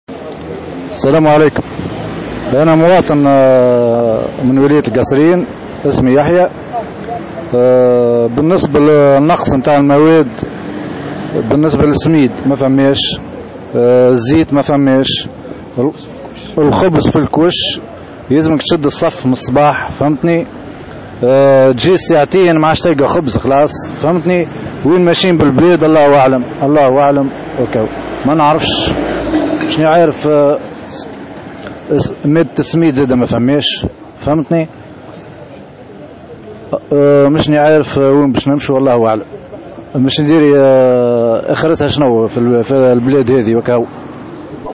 Les habitants de Kasserine sont intervenus aujourd’hui au micro de Tunisie Numérique pour exprimer leur colère face à la pénurie de certains matières premières dont la semoule et la farine.